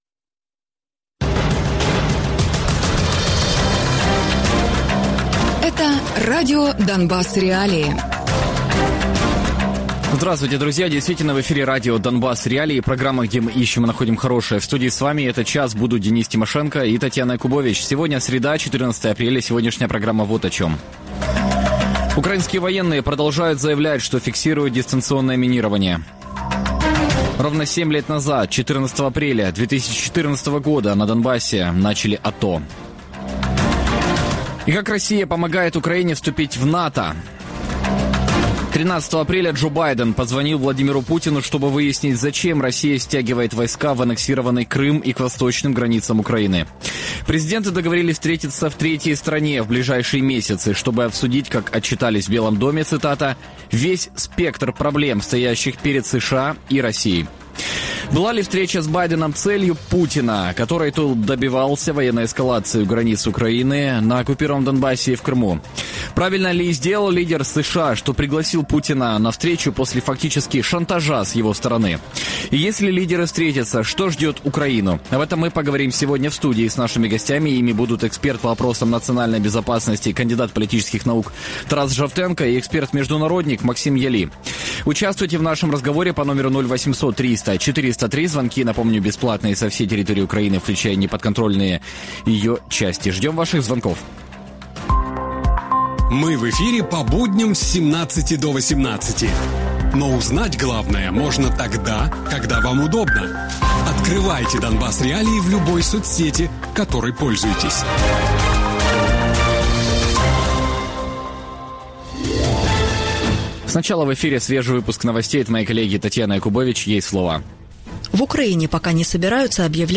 Гості програми радіо Донбас.Реалії